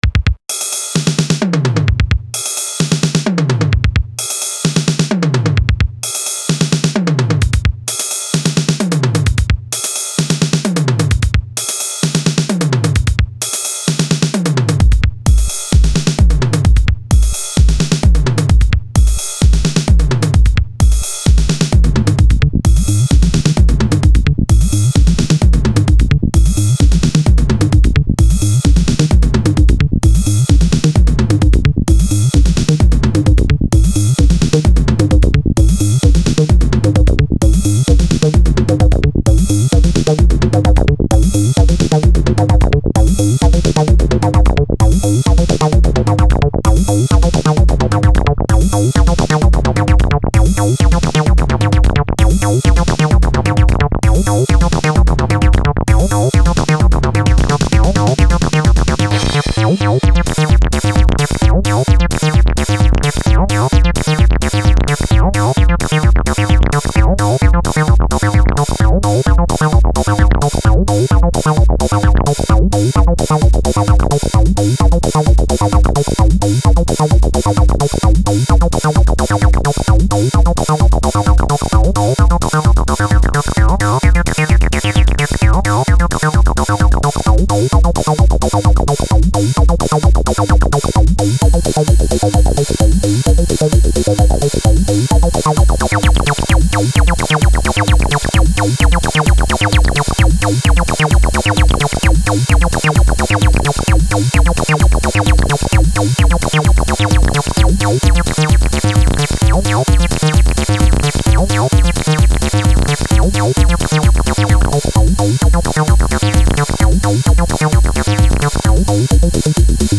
acid techno trance edm breakcore,